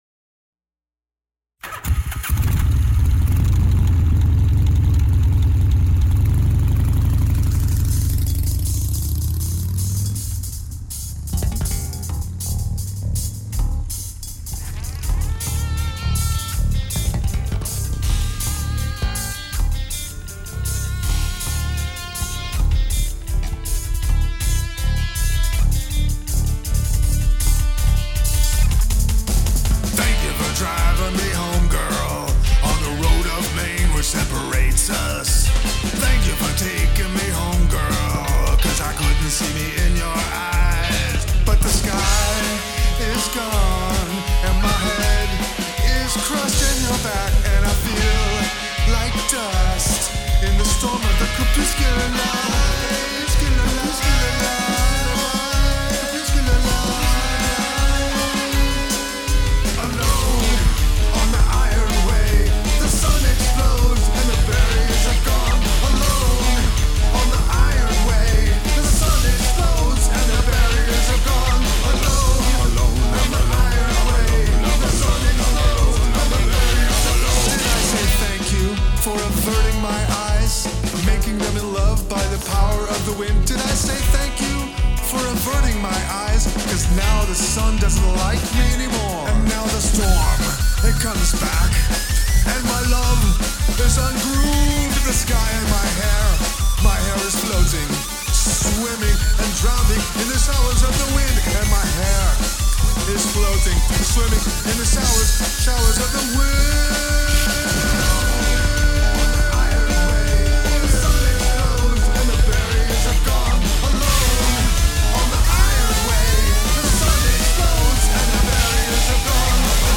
Rock Singer
quelque peu remaniée and just a little bit « punkifiée »!
guitares